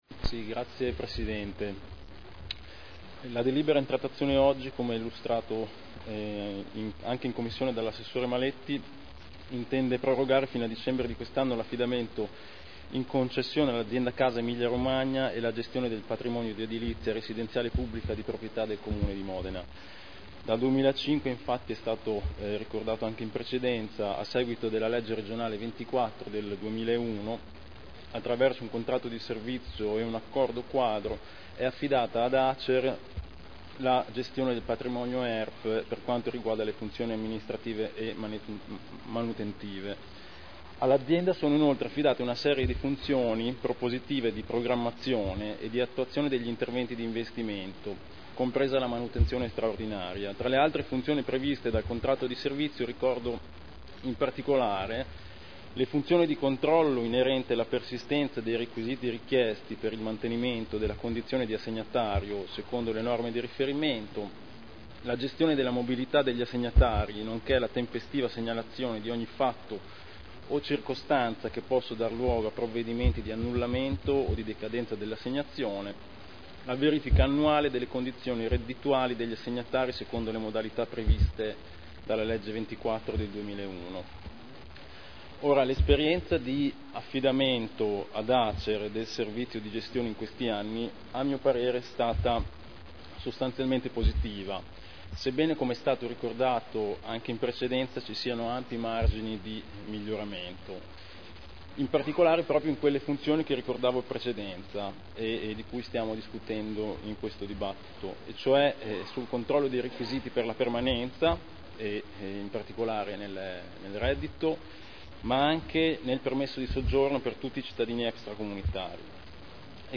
Stefano Rimini — Sito Audio Consiglio Comunale